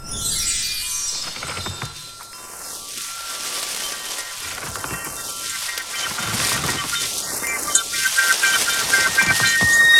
Коллапс_аномалии_флоры.mp3